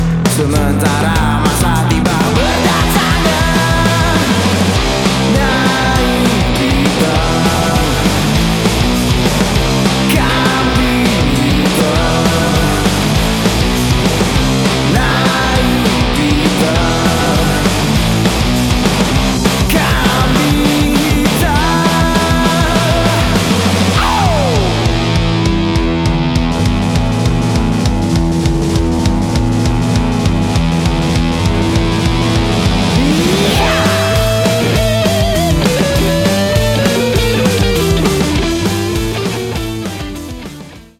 band rock